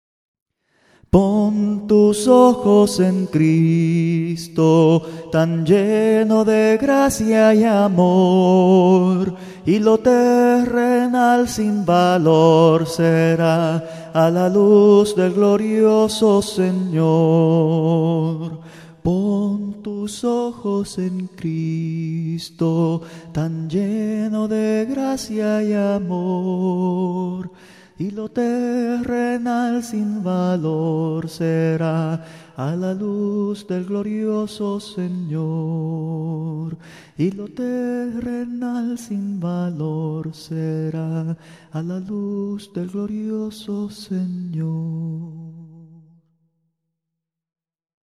Voz de Mando Únicamente: